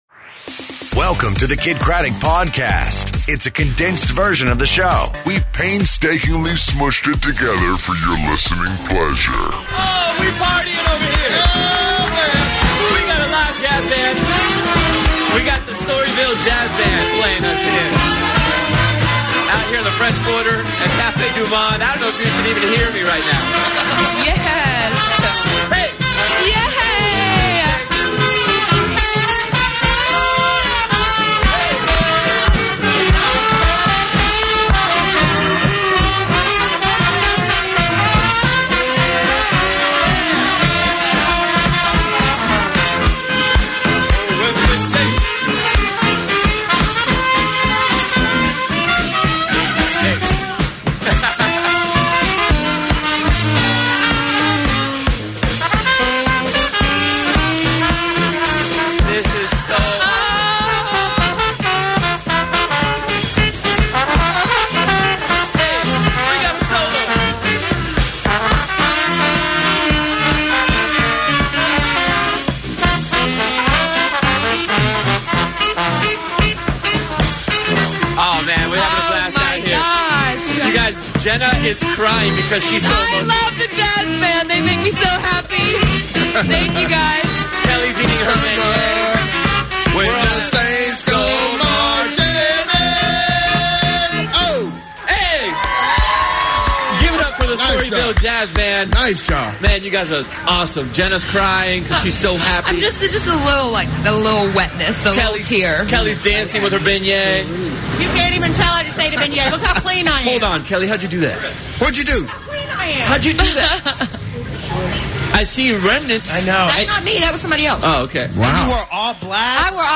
Our Last Show Of The Year Live From New Orleans!